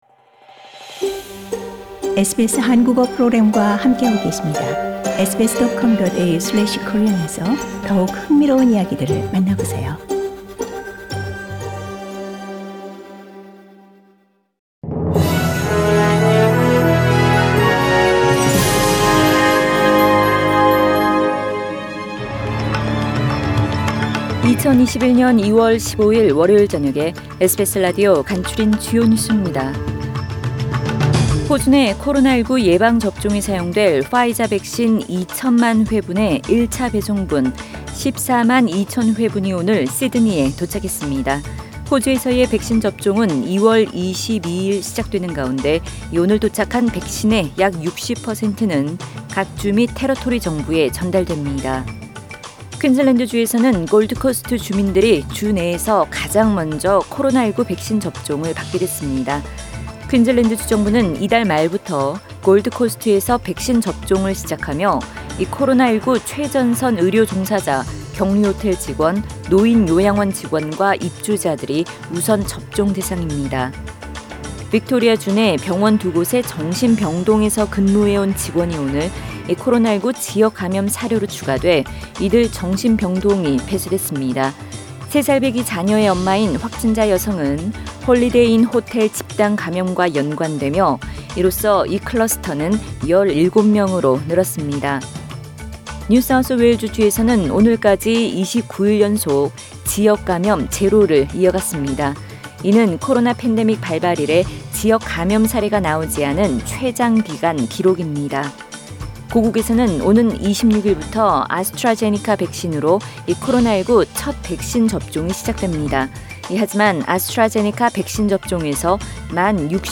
2021년 2월 15일 월요일 저녁의 SBS 뉴스 아우트라인입니다.